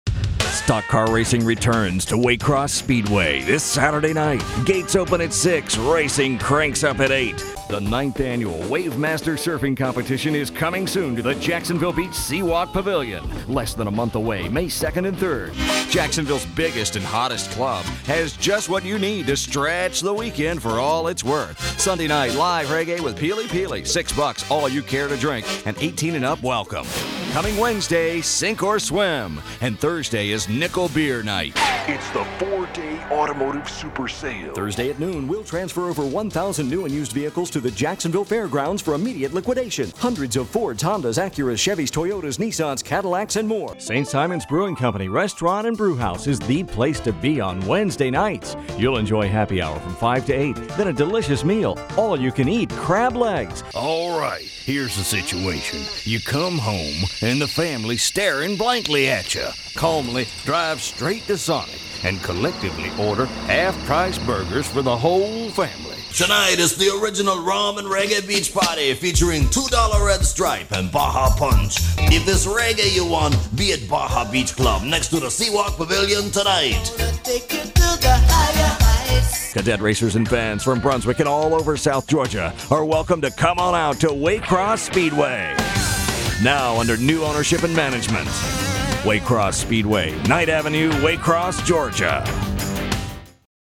Warm calm friendly ear massaging voice for narrations, dynamic funny affirrmative active for commercials, smooth professional corporate for presentations and flexible chameleon for characters....
0723Ninety_Second_Commercial_Demo_Radio_-_All_Voices.mp3